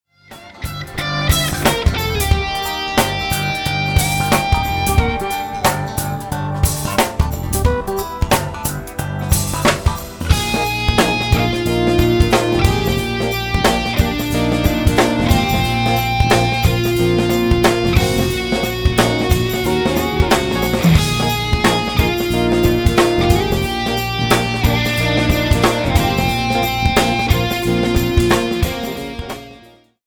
A play-along track in the style of fusion.
It is in the style of fusion.